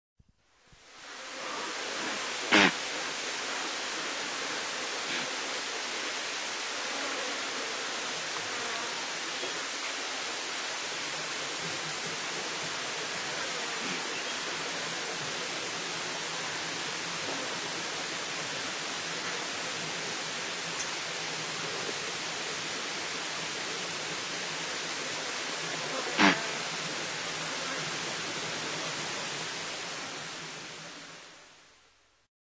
Звук газели Томпсона среди жужжания насекомых